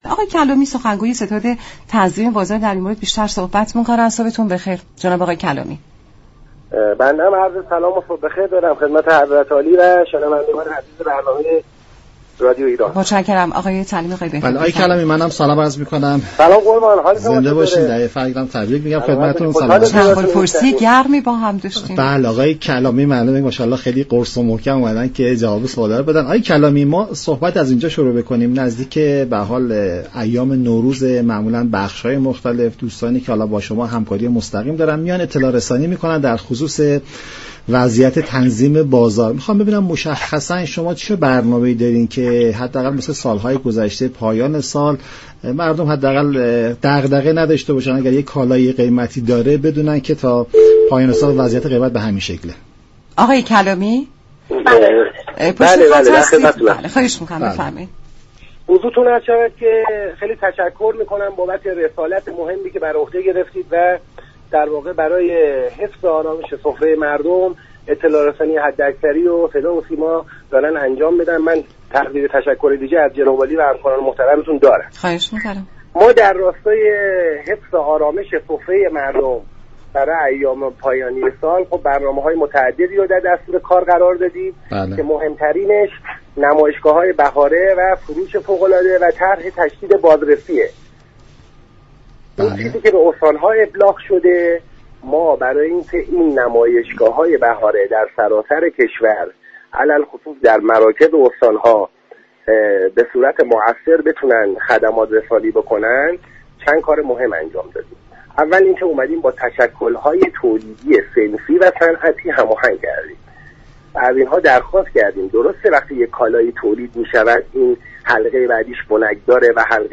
مدیركل تامین، توزیع و تنظیم بازار وزارت صمت در گفت و گو با رادیو ایران گفت: برای رفاه حال بیشتر مردم ، آمادگی داریم برای كالاهای اساسی غرفه ها را به شكلی رایگان در اختیار واحدهای صنفی، تولیدی، صنعتی قرار دهیم.